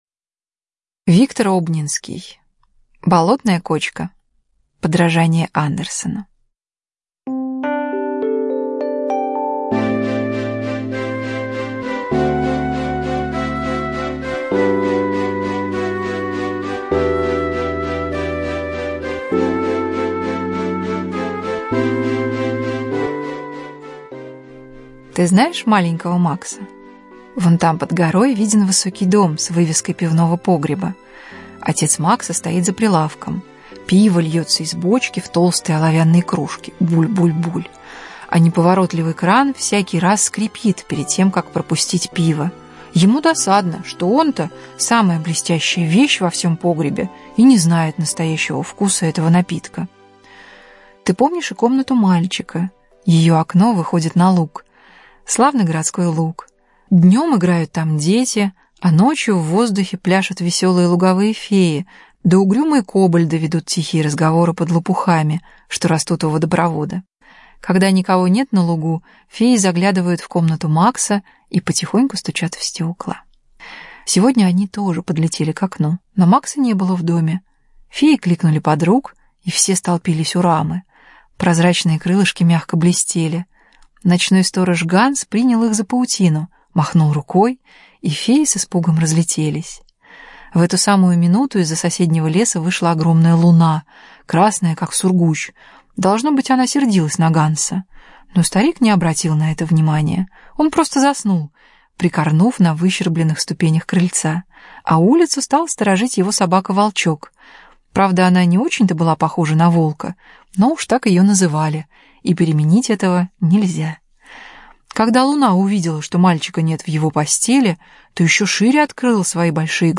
Болотная кочка - аудиосказка Обнинского - слушать онлайн